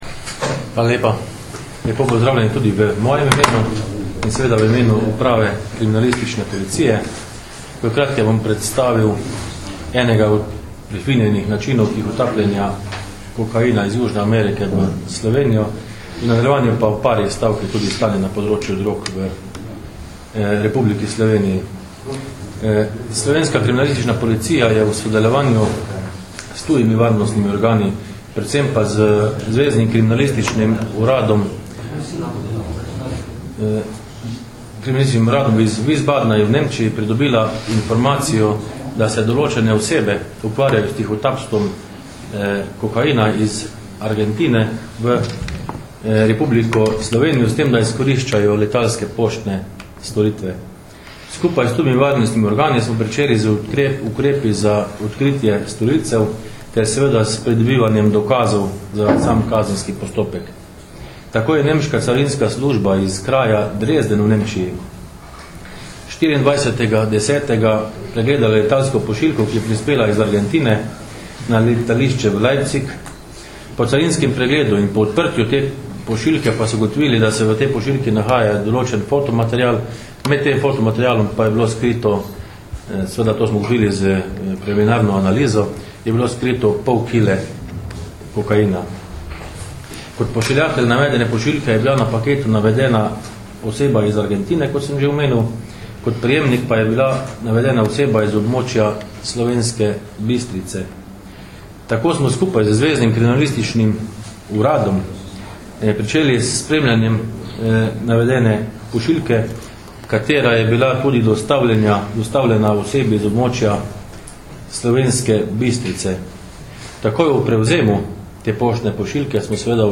Predstavniki slovenske policije in carine so na novinarski konferenci v Mariboru danes, 3. novembra 2009, podrobneje predstavili enega večjih zasegov prepovedane droge v zadnjem času.
Zvočni posnetek izjave